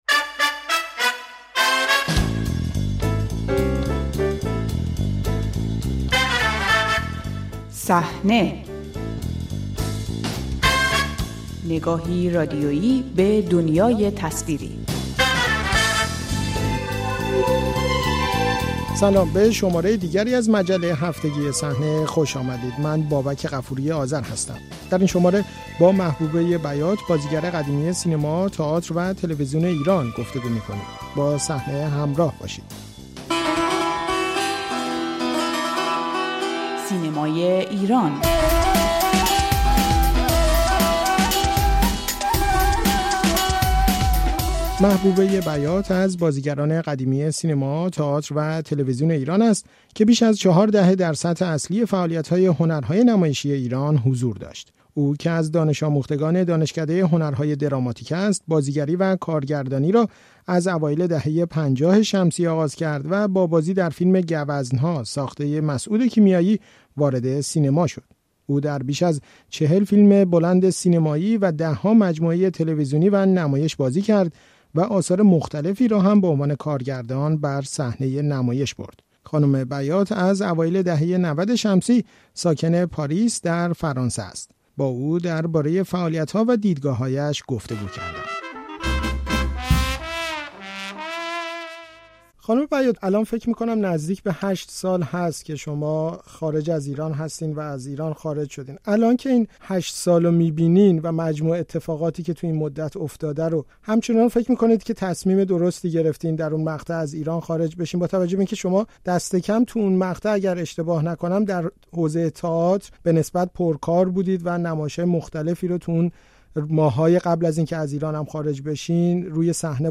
سمت درست تاریخ؛ گفت‌وگو با محبوبه بیات بازیگر شناخته‌شده سینما و تئاتر ایران